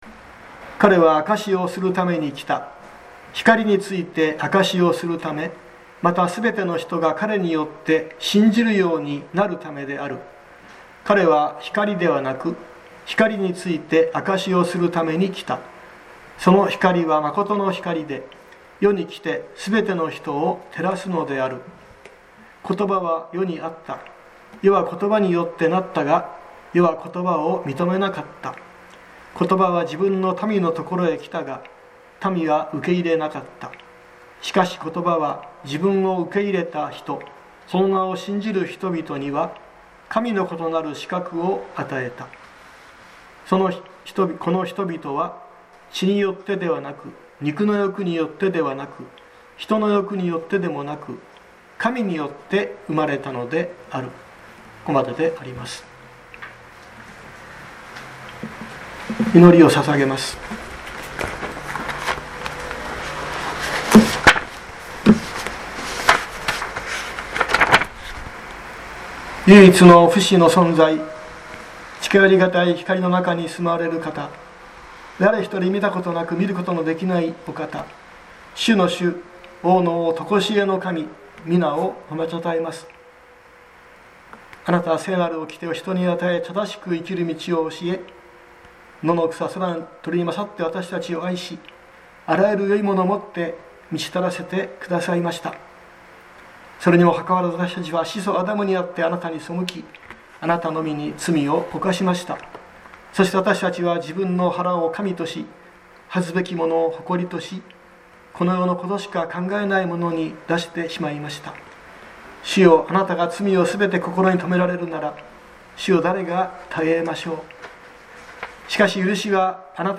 2022年04月24日朝の礼拝「神の子の資格」熊本教会
説教アーカイブ。